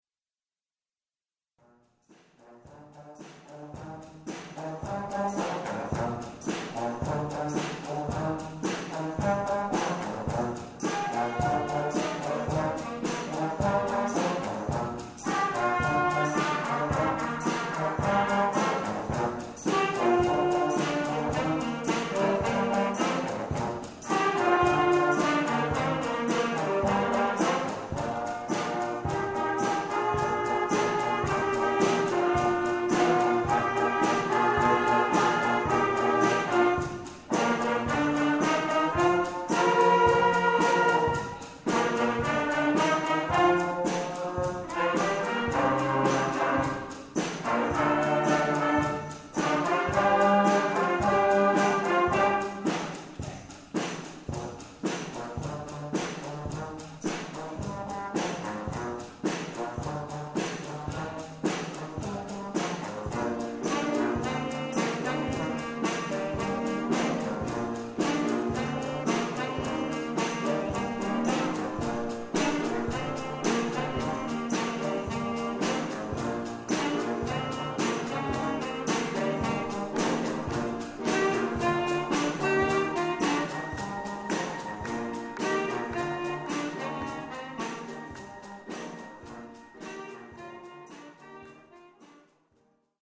Bläserklassen-Wettbewerb Rhein-Ruhr in Essen - Finale
Bronze für unsere Bläserklasse 6D!
Insgesamt präsentierten 11 Bläserklassen auf der Bühne des Musikpavillons ihr anspruchsvolles Programm und zeigten in ihren Beiträgen ein beachtliches Können.